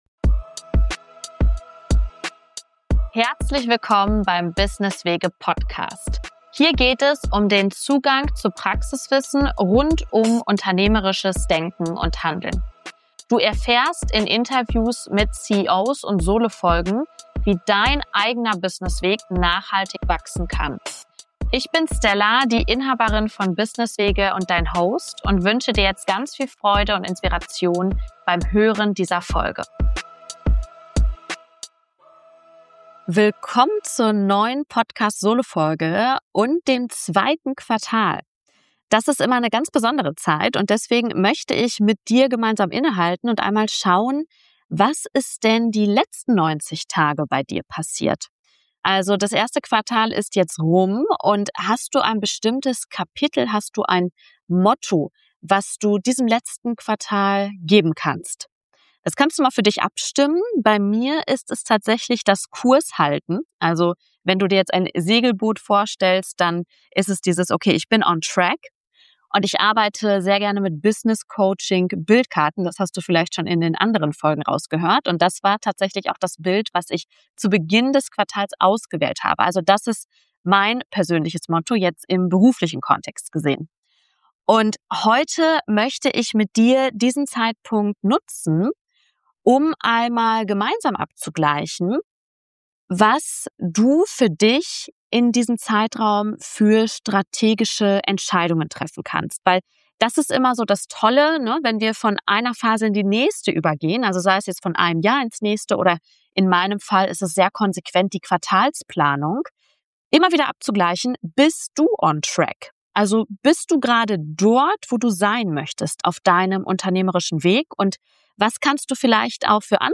In dieser Solo-Folge zum Start des zweiten Quartals lade ich dich ein, gemeinsam mit mir innezuhalten und auf die letzten 90 Tage zurückzublicken. Mein Motto für dieses Quartal: Kurshalten.